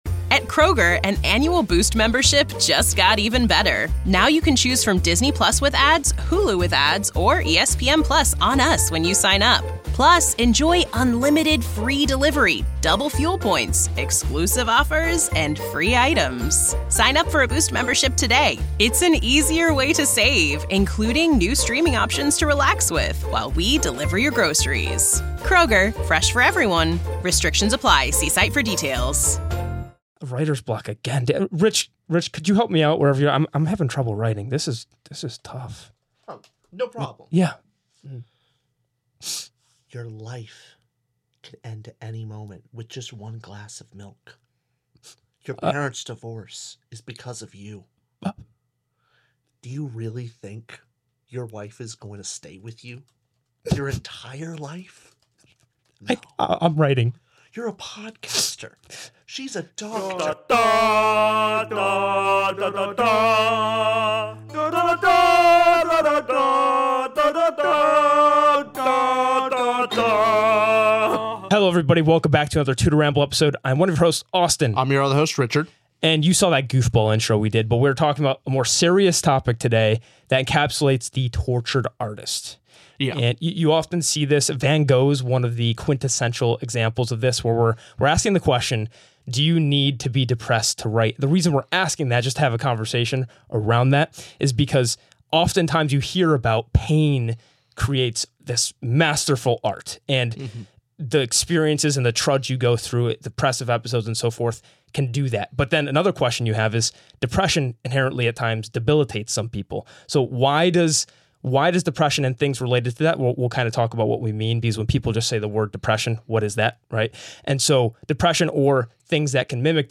2 guys talking about books (mostly fantasy & sci-fi).